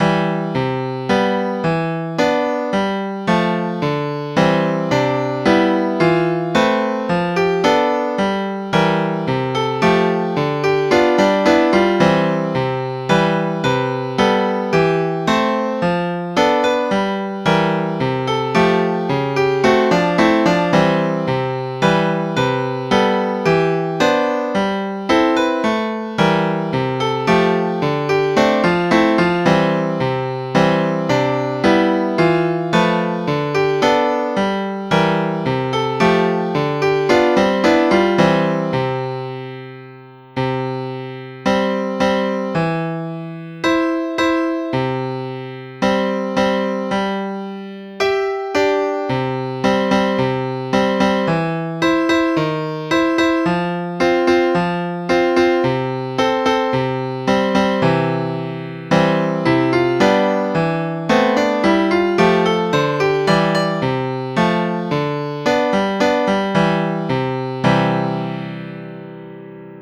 東方頌鋼琴.wav